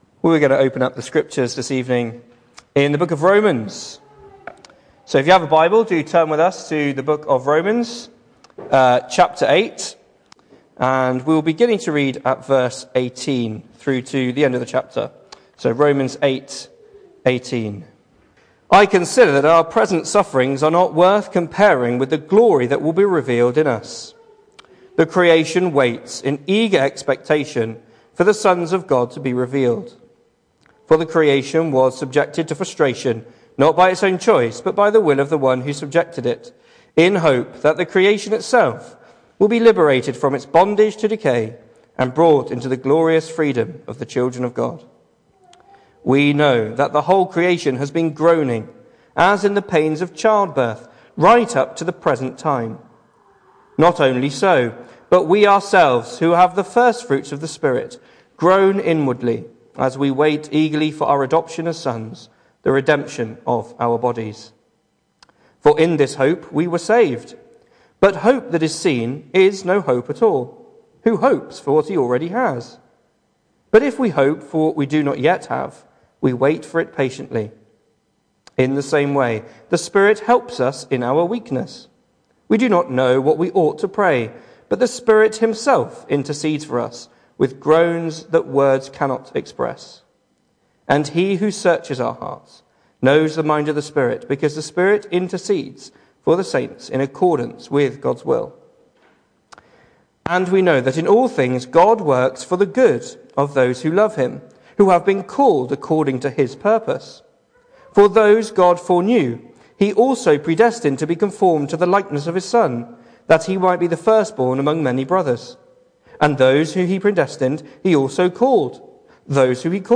The 2nd of November saw us hold our evening service from the building, with a livestream available via Facebook.